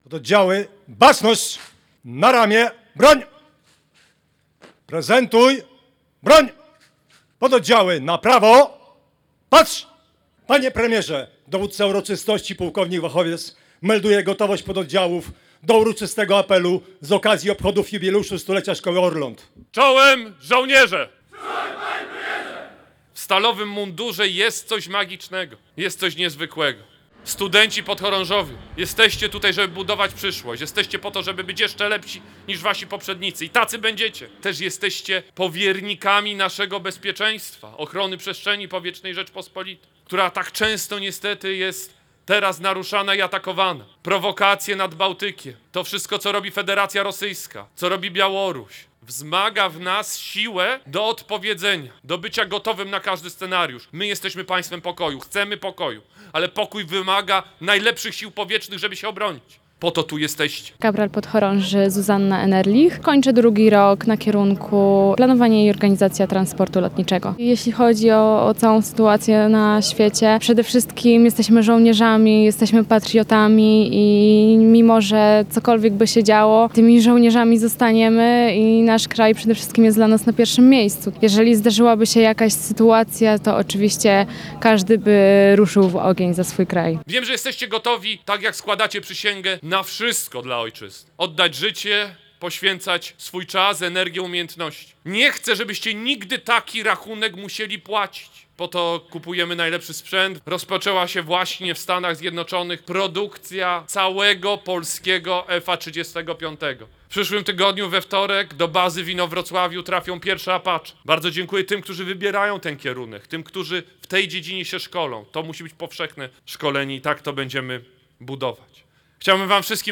O historii, teraźniejszości, ale i wyzwaniach mówiono podczas stulecia Szkoły Orląt w Dęblinie. Główne obchody jubileuszowe zorganizowano dziś przed dęblińskim garnizonem. Uczestniczył w nich wicepremier, minister obrony narodowej Władysław Kosiniak-Kamysz.